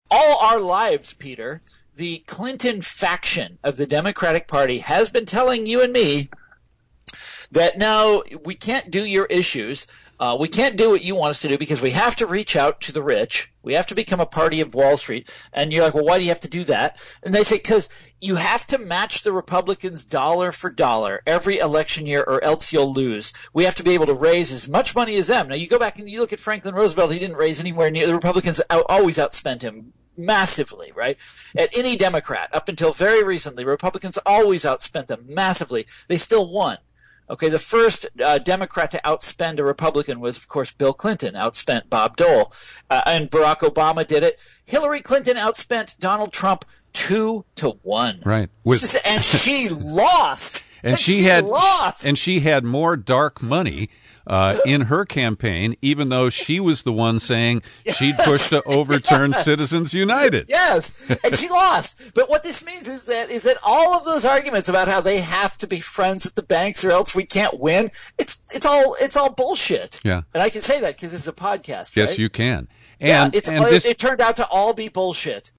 In-Depth Interview: Thomas Frank Analyzes Democrats’ Losses, Celebrates End of Clinton, Bush Dynasties